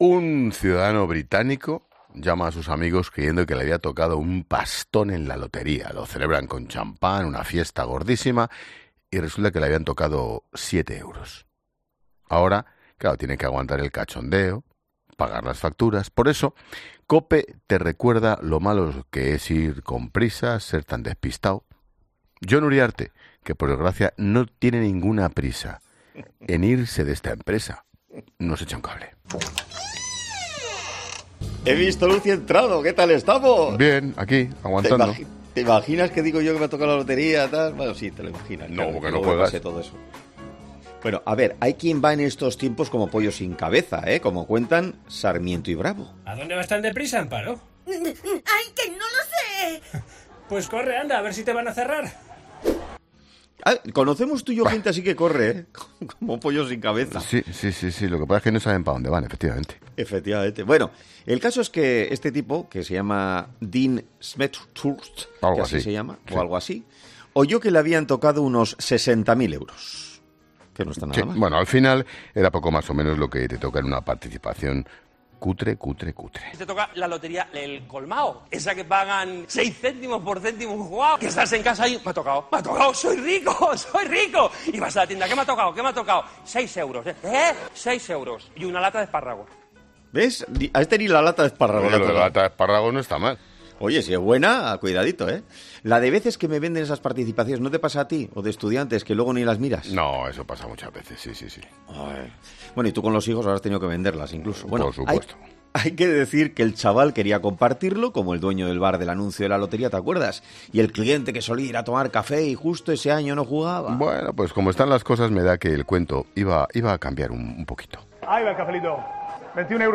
El director de La Linterna, Ángel Expósito